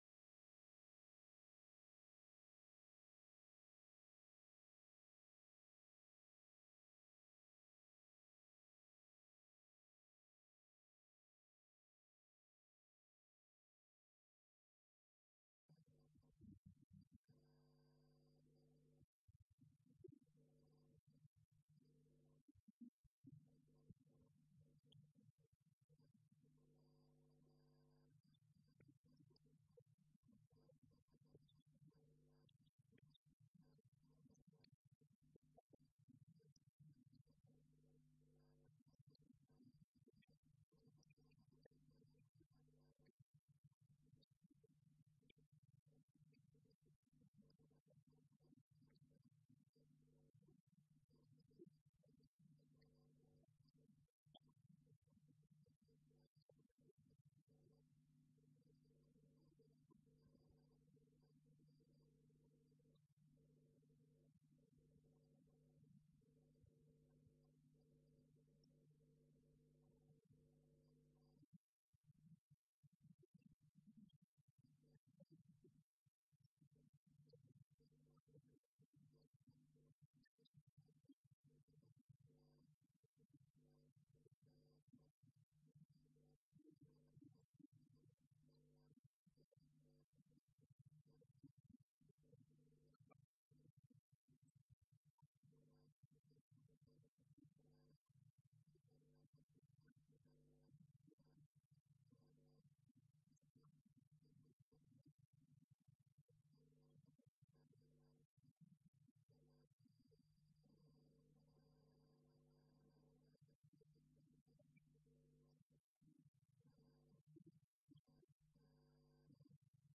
10 Débat autour de la session : Traduction, langage et pensée philosophique | Canal U
Colloque L’Islam et l’Occident à l’époque médiévale.